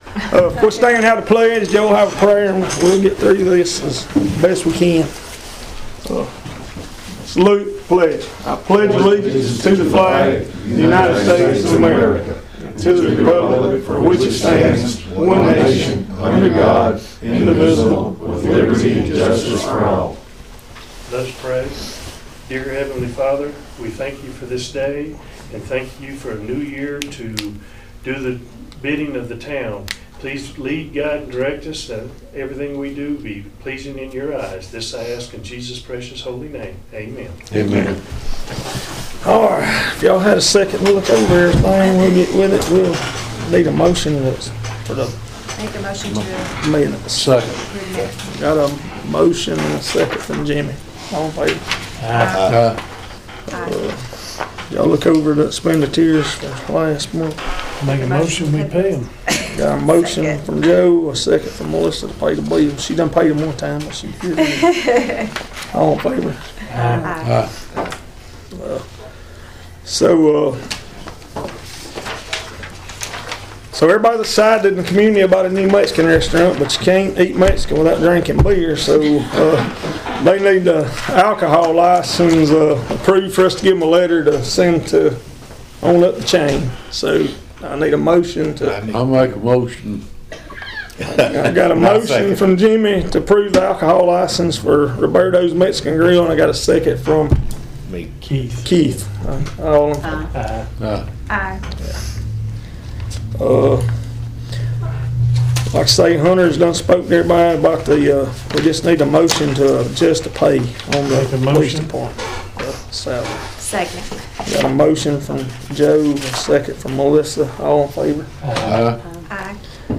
LEESBURG, Ala.– The Leesburg Town Council met Monday afternoon, opening the meeting with the Pledge of Allegiance and an invocation before approving the minutes from the previous meeting.